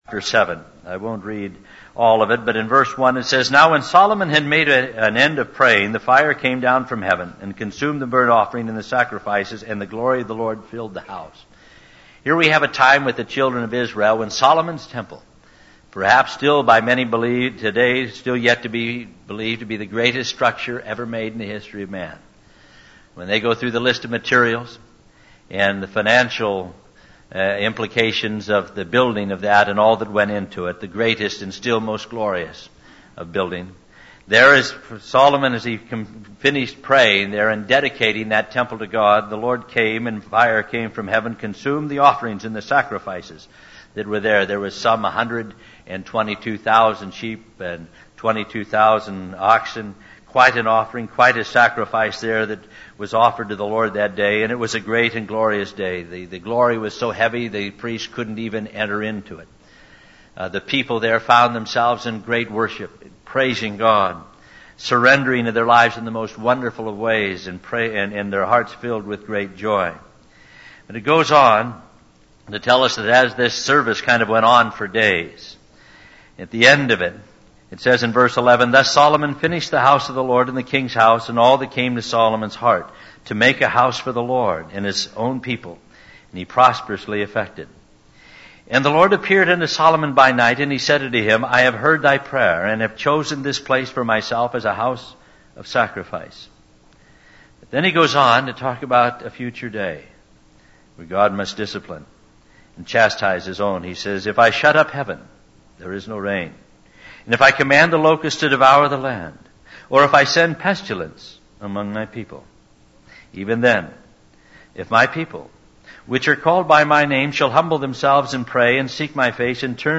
In this sermon, the speaker emphasizes the importance of humbling oneself, praying, seeking God's face, and turning away from wicked ways in order to receive forgiveness and healing from God. The speaker relates this message to the recent tragic events of the plane crashes and encourages listeners to take this opportunity to share the love of God and hope in Christ with others.